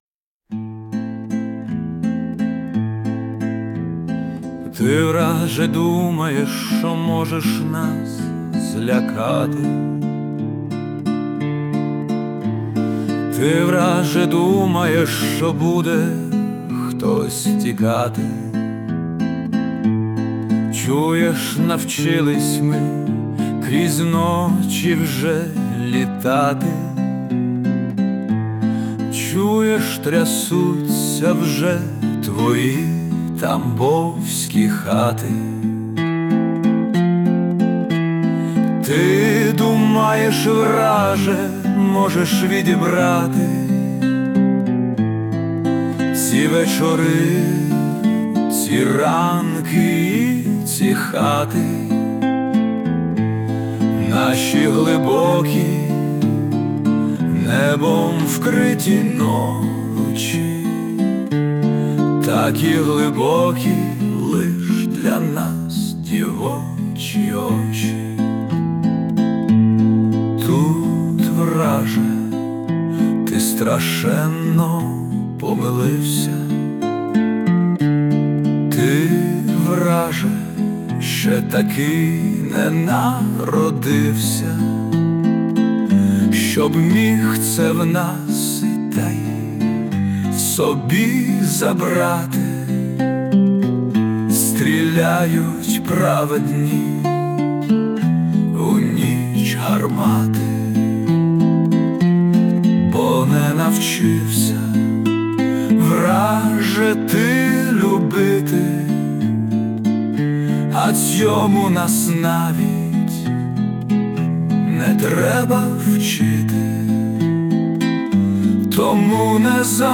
Вражаюче... пробирає до глибини душі: трагізм війни і гордість за наших захиснків. 17 17 Спасибі Вам за чудові слова і зворушливо-чуттєву пісню, пройняті болем. hi 12 12
Голос проникливий і... слова чутливі та правильні...
Дякую Вам за чудову громадянську поезію, яка за допомогою ШІ стала гарною піснею! 12 12 12